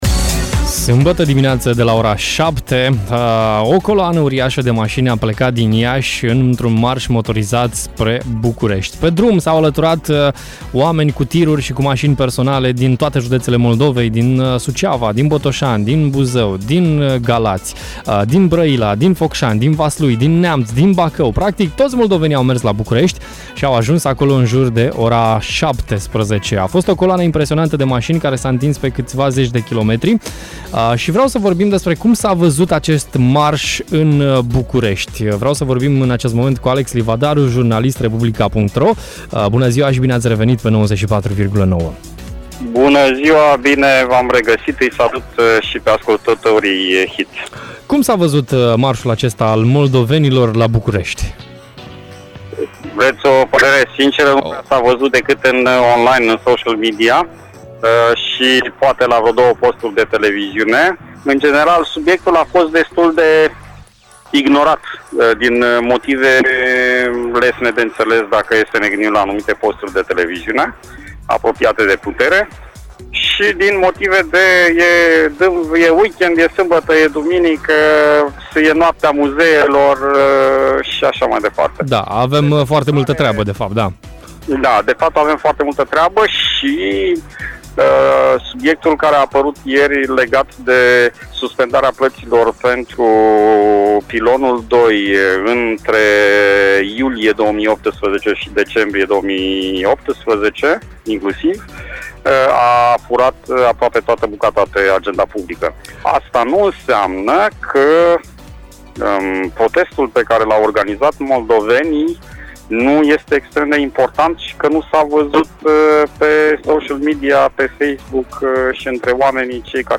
astăzi am vorbit in direct la Radio Hit